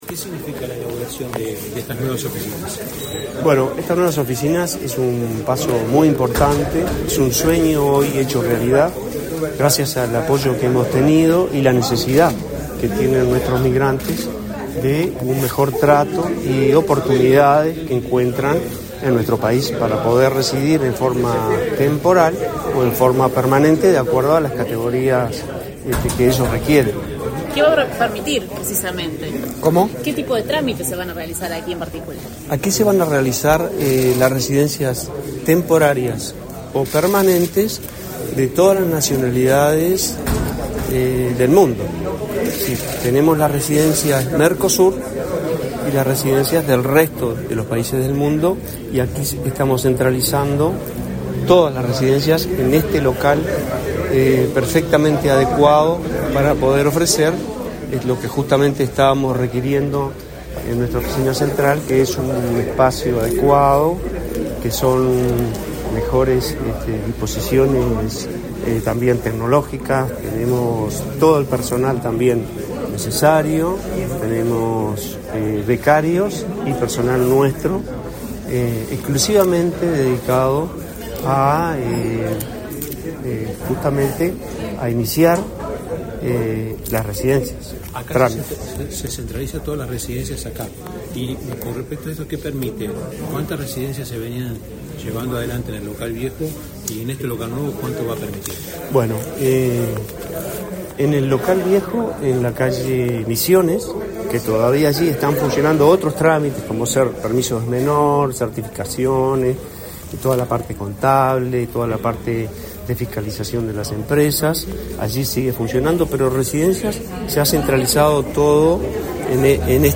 Declaraciones a la prensa del director nacional de Migraciones, Eduardo Mata
Declaraciones a la prensa del director nacional de Migraciones, Eduardo Mata 30/05/2024 Compartir Facebook X Copiar enlace WhatsApp LinkedIn Tras participar en la inauguración del Departamento de Residencias de la Dirección Nacional de Migración, este 30 de mayo, el director nacional de Migraciones, Eduardo Mata, realizó declaraciones a la prensa.